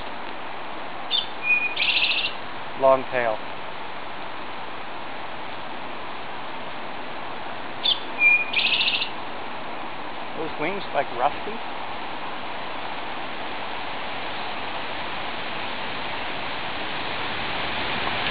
unknown bird
bird song)   this unidentified bird (about 7") was singing from the treetops on wintrop hill. even after consulting with various birding field guides, i still can determine what bird it was. hopefully somebody can tell me.
unknown_singing_bird.wav